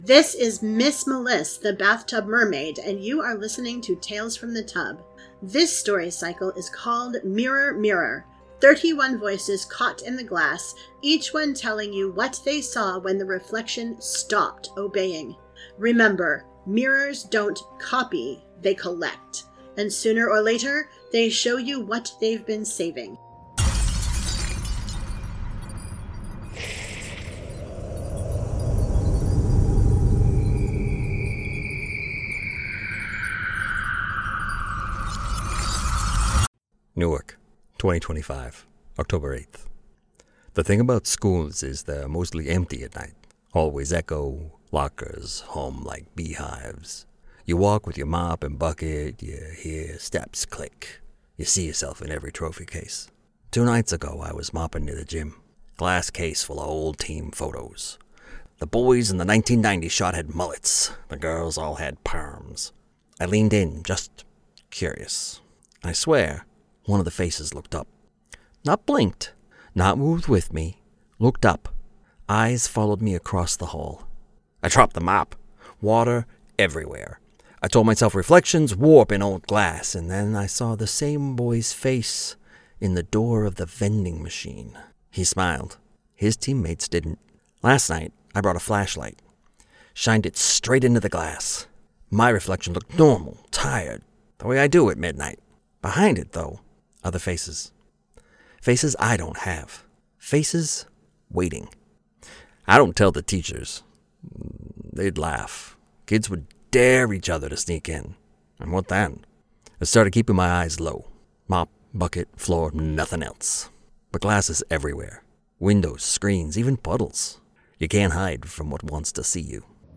This is Episode Eight of Mirror, Mirror, a story cycle of thirty-one monologues on Tales from the Tub.